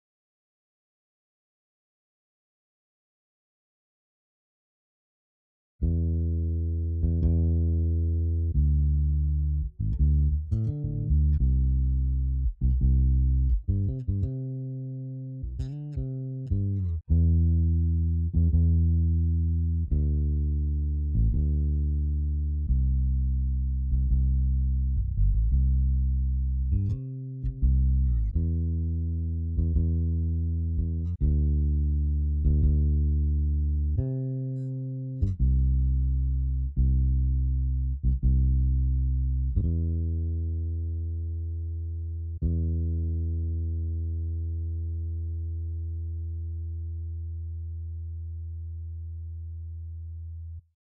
| Em7 | Em7/D | CMaj7 | |
I correctly hear the D slash note on bar 2.
908_BBBass_Real_SingleRender_DragDrop_0.WMA